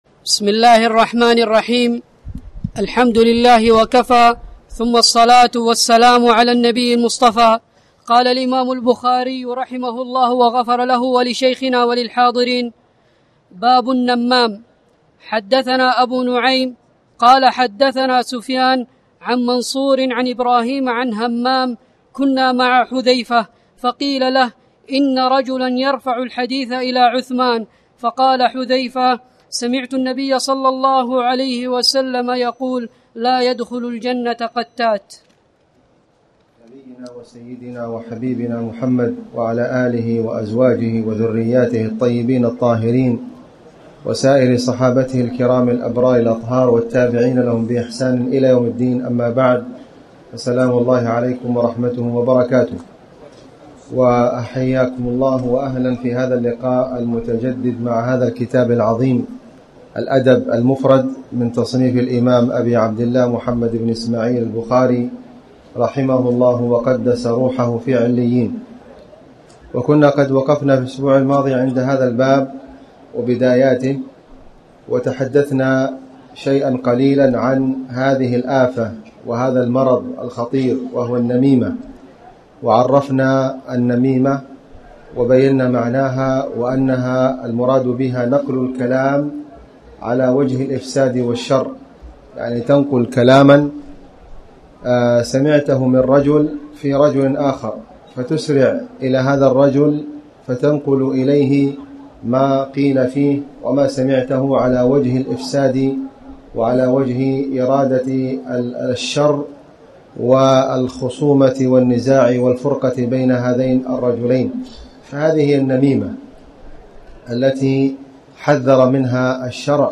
تاريخ النشر ١٦ ربيع الأول ١٤٣٩ هـ المكان: المسجد الحرام الشيخ: خالد بن علي الغامدي خالد بن علي الغامدي باب النمام